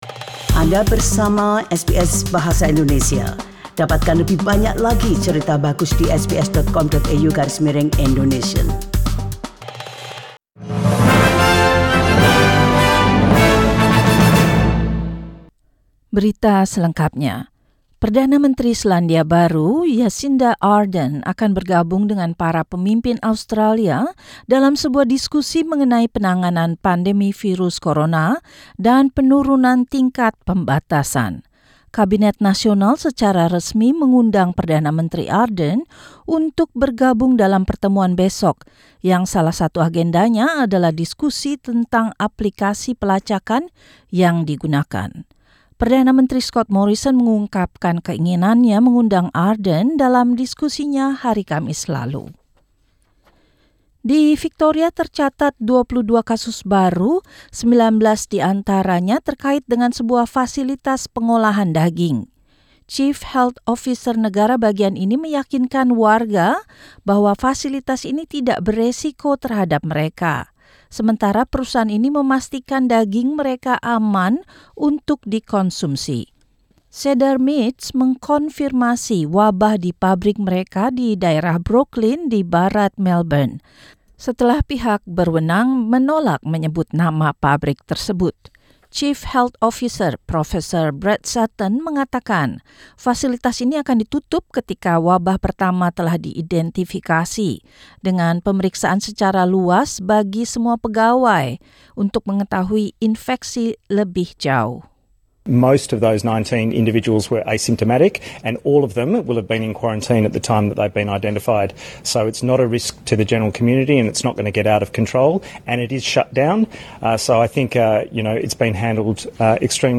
SBS Radio News presented in Indonesian - Monday, 4 May 2020